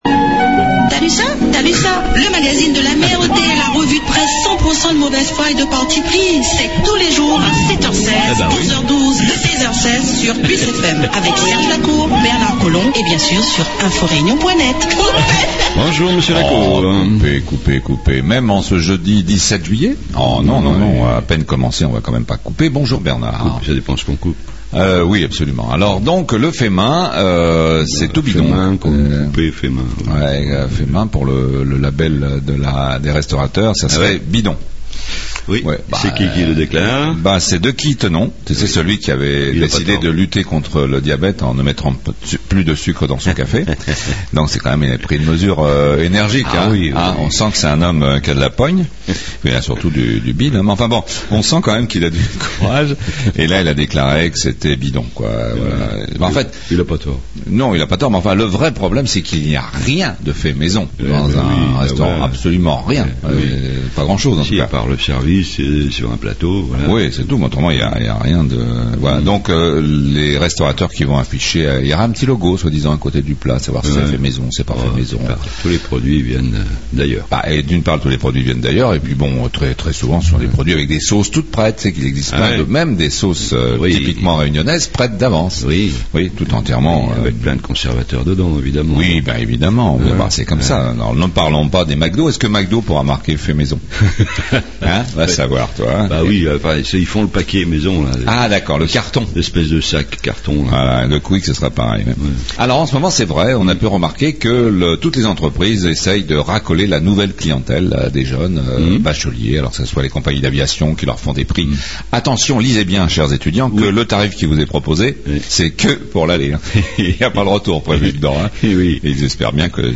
REVUE DE PRESSE Jeudi 17 Juillet 2014 Le JIR : Le " Fait maison" , c'est bidon !!